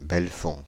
Belfonds (French pronunciation: [bɛlfɔ̃]
Fr-Belfonds.ogg.mp3